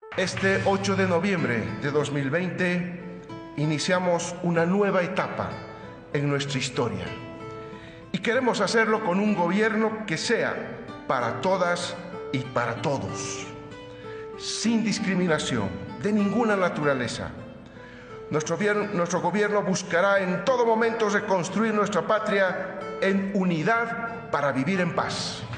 English: Speech delivered by Luis Arce at his inauguration as the 67th President of the Plurinational State of Bolivia
Luis_Arce_-_Speech.ogg